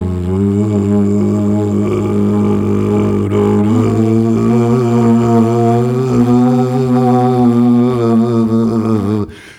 Flute 51-04.wav